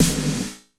Free MP3 Roland TR606 - Snare drums 2
Snare - Roland TR 30